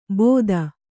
♪ bōda